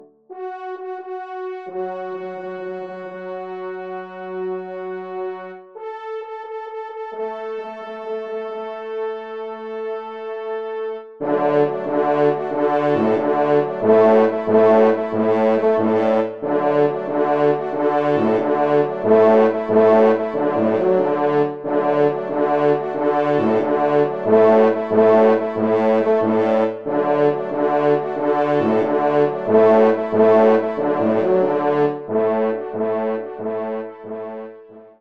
Genre : Divertissement pour Trompes ou Cors
Pupitre 5° Cor